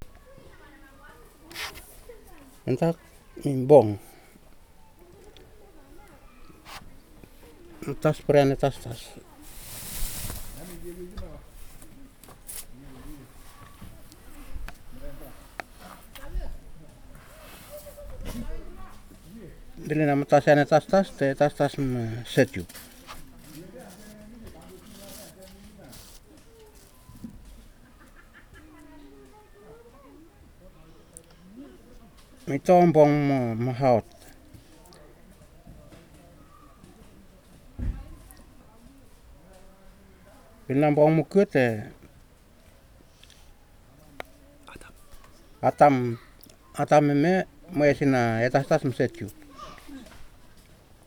Storyboard elicitation of argument structure alternations (Storyboard: The old bench).
digital wav file recorded at 44.1 kHz/16 bit on Marantz PMD 620 recorder
Emiotungan, Ambrym, Vanuatu